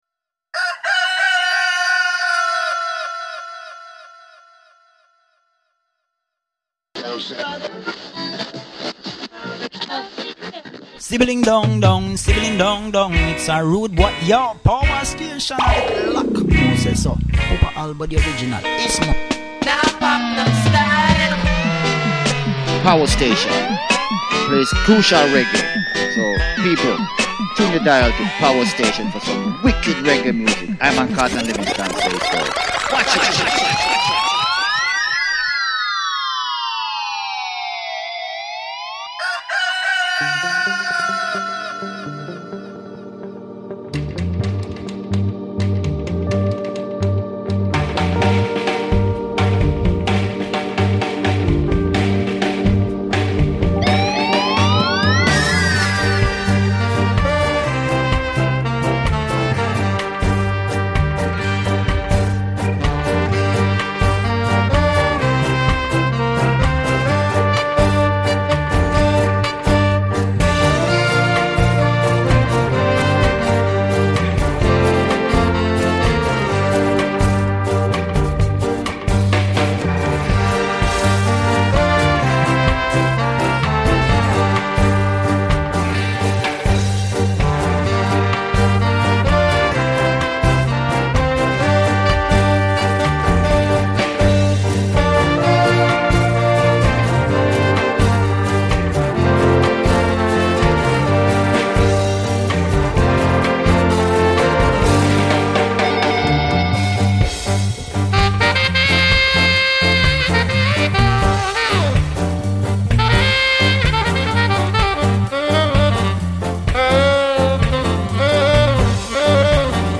dub , reggae , ska